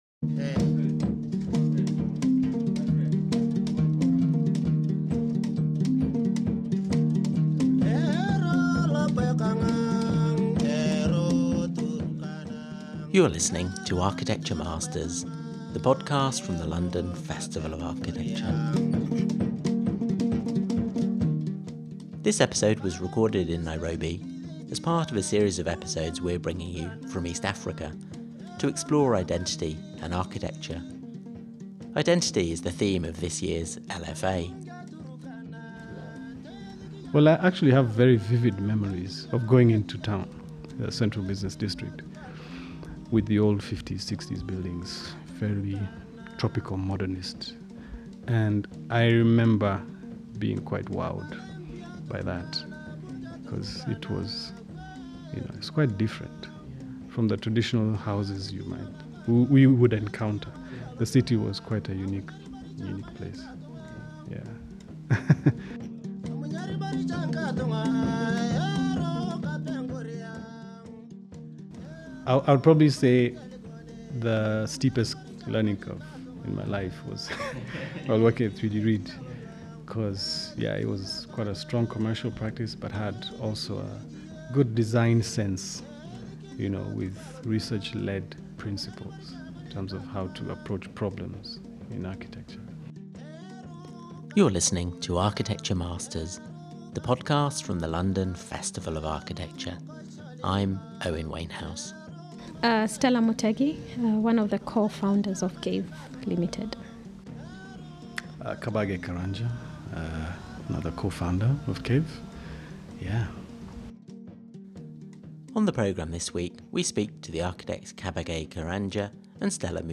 This episode was recorded in Nairobi as part of a series of episodes we’re bringing you from East Africa to explore identity and architecture.